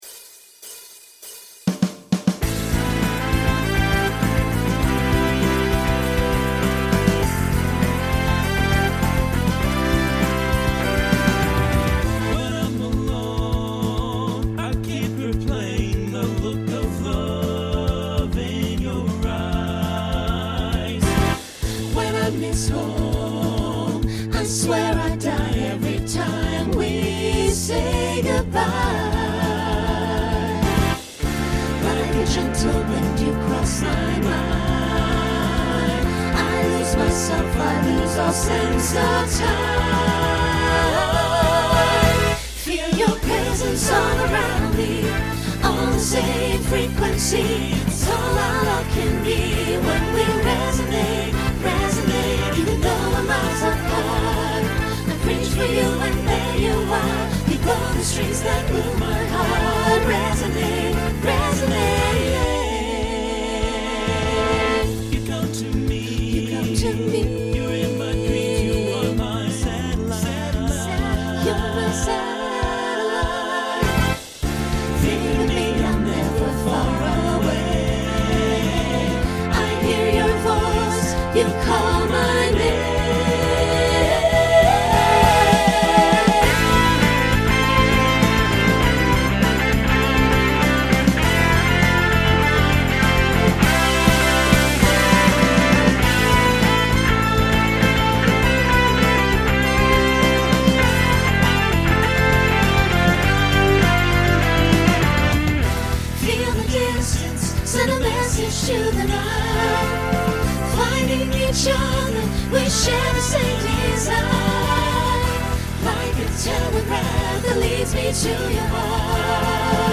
Genre Rock Instrumental combo
Mid-tempo , Opener Voicing SATB